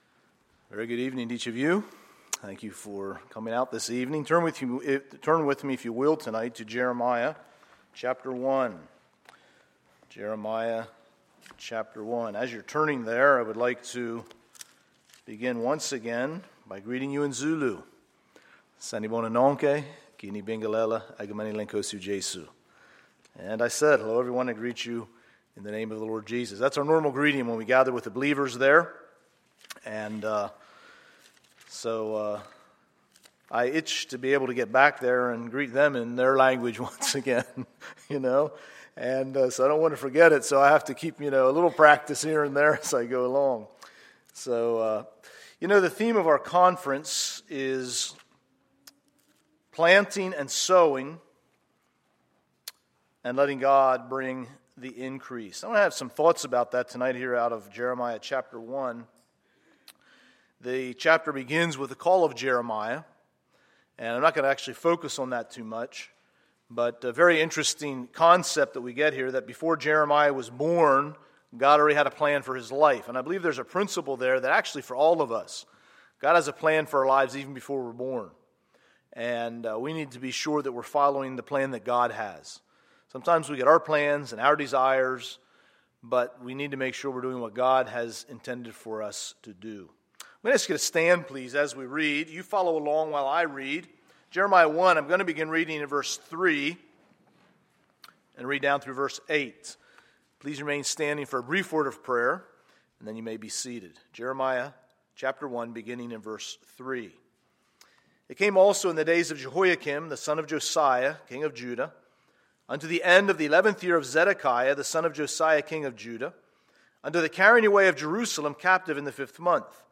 Sunday, September 21, 2014 – Sunday Evening Service